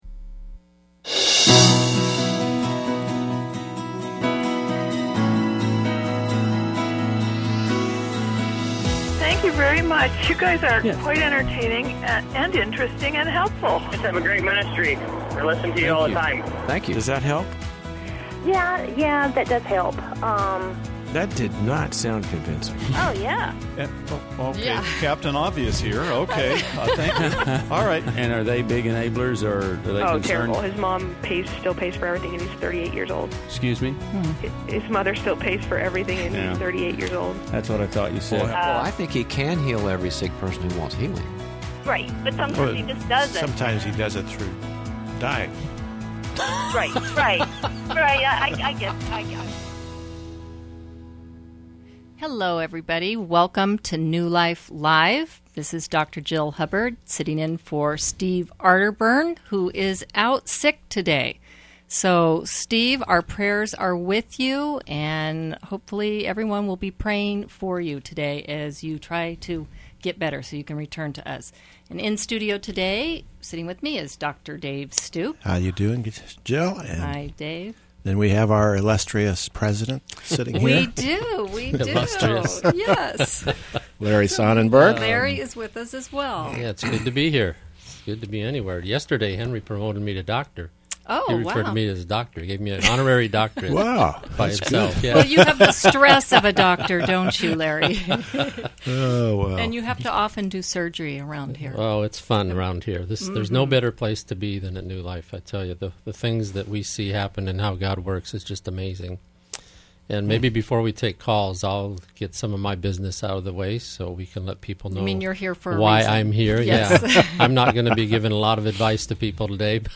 Caller Questions: We are adopting a 13yo girl from China; how do we handle her anger?